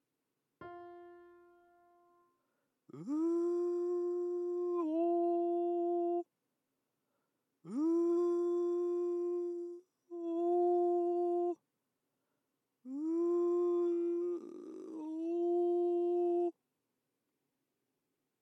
音量注意！
このように途切れてしまったり、母音が急激に変わってしまったら、この練習の場合はNGです。